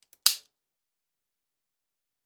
Звуки револьвера
Щелчок холостого револьвера (пытаешься выстрелить, но он не стреляет)